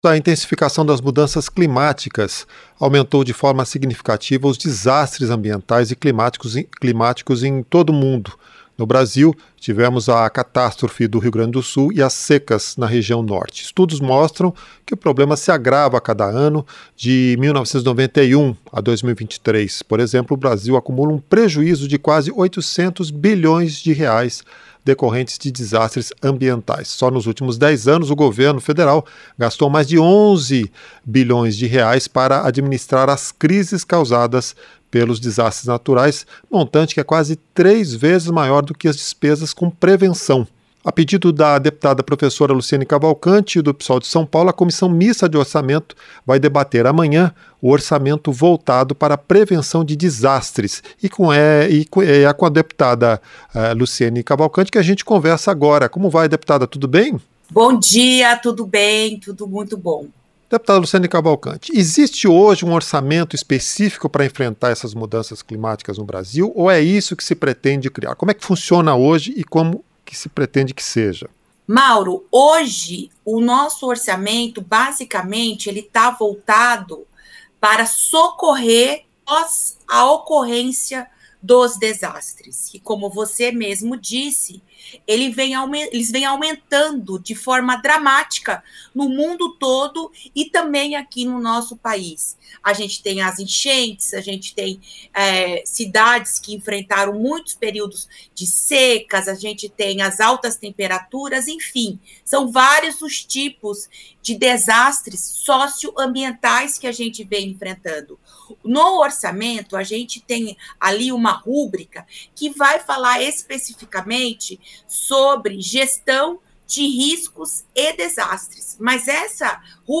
Entrevista - Dep. Prof. Luciene Cavalcante (PSOL-SP)